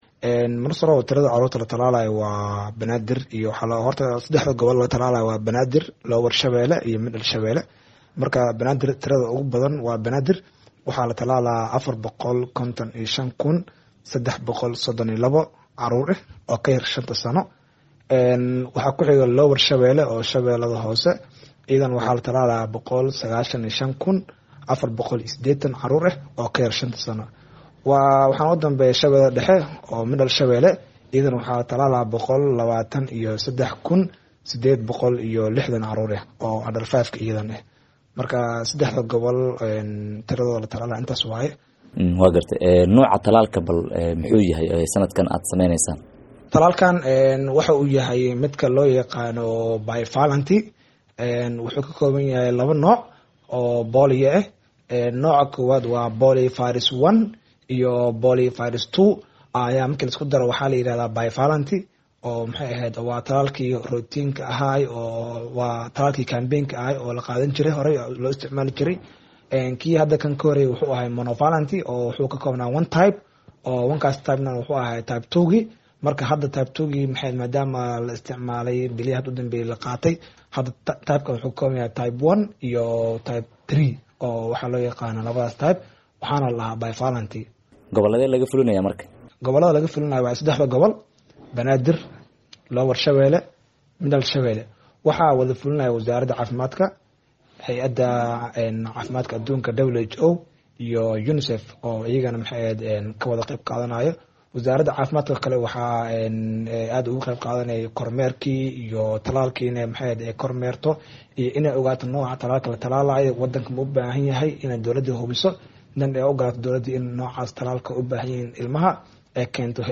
WAREYSI-TALAALKA-CUDURKA-POLIO-OO-MUQDISHO-KA-SOCDA.mp3